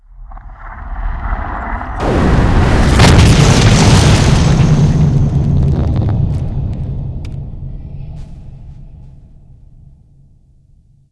sb_mockup_explo.wav